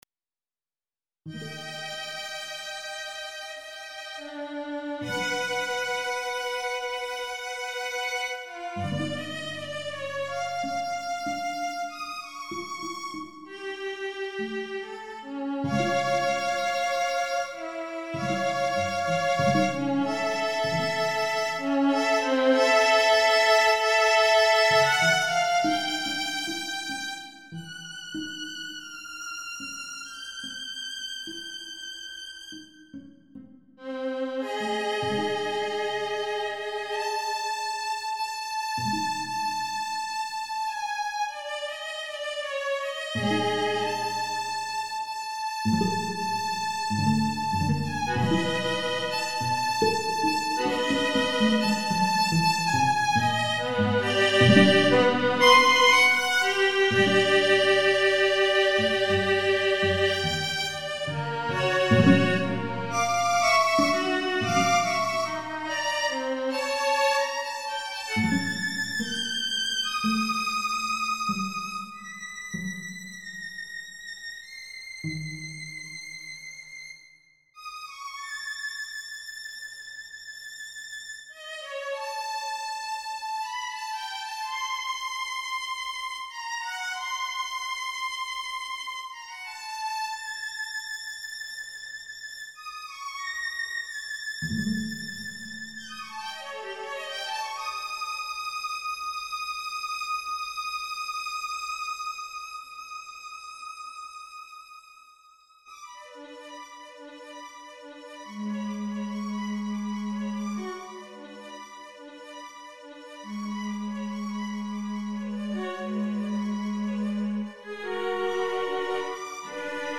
Unperformed (live) so computer generated recording - apologies...
1. Lento melancolico - Doppo movimento, delicato